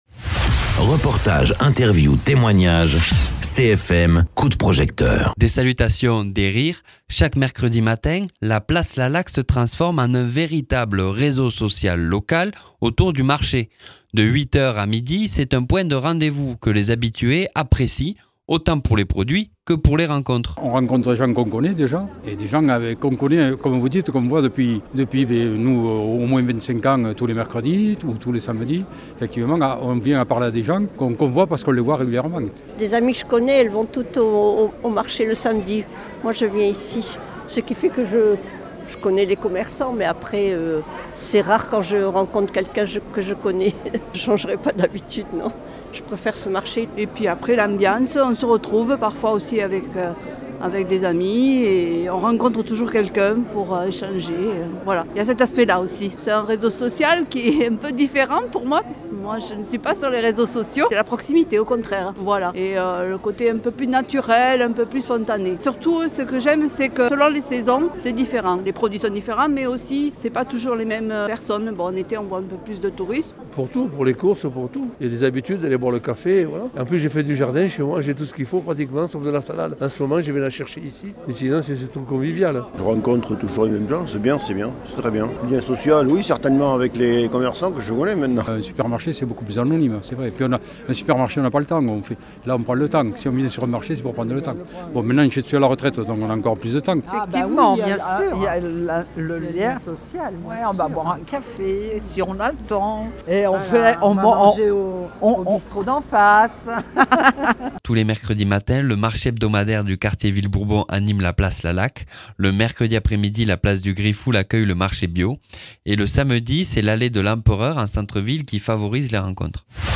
Des salutations, des rires. Chaque mercredi matin, la place Lalaque se transforme en véritable réseau social local autour du marché.
Interviews